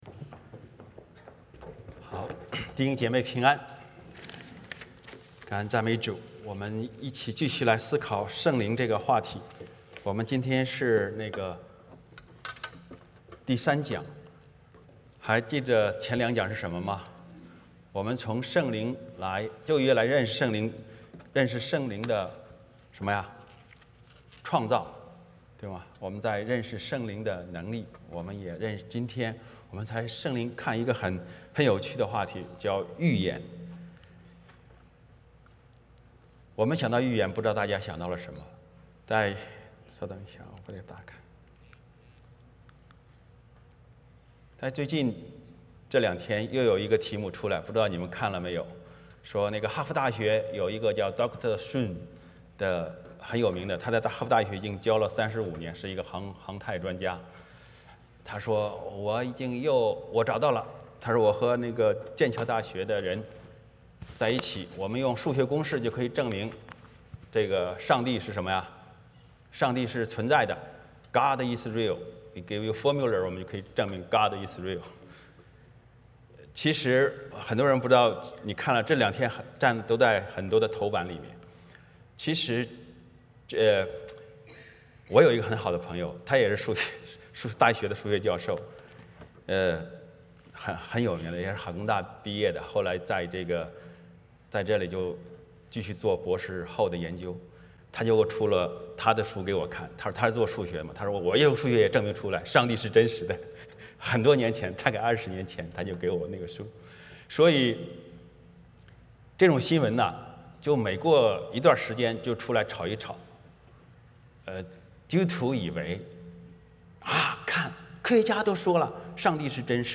彼得后书 1:20-2:3 Service Type: 主日崇拜 欢迎大家加入我们的敬拜。